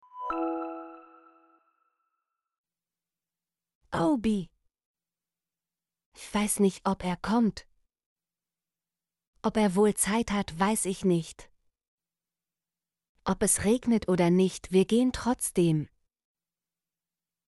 ob - Example Sentences & Pronunciation, German Frequency List